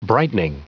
Prononciation du mot brightening en anglais (fichier audio)
Prononciation du mot : brightening